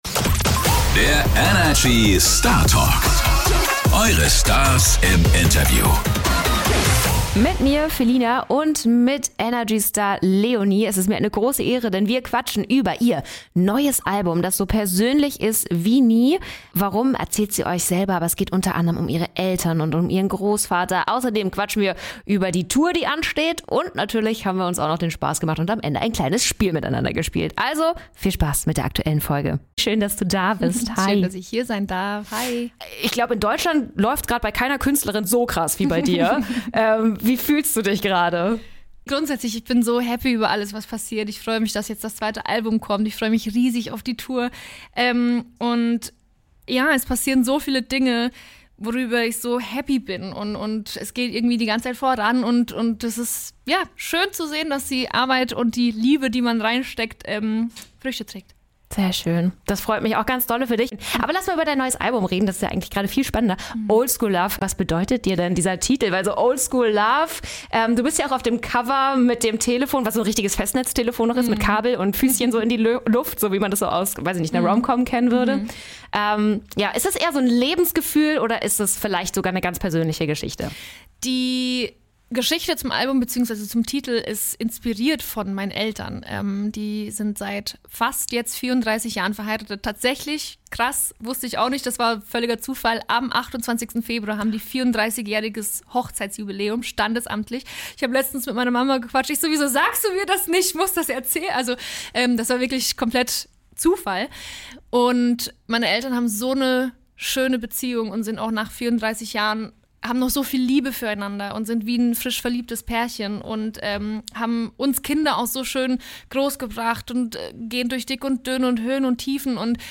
Beschreibung vor 1 Jahr Sie ist eine der, wenn nicht sogar DIE bekannteste deutsche Pop-Sängerin unserer Zeit und jetzt ist sie mit ihrem zweiten Album "Oldschool Love" am Start: In dieser Startalk-Folge sprechen wir mit Leony über ihr neues Album, über die Beziehung ihrer Eltern und erfahren, wie es eigentlich um ihren Beziehungsstatus steht. Außerdem geht sie schon ganz bald auf Tour und verrät uns schonmal exklusiv, was wir da aufregendes erwarten können.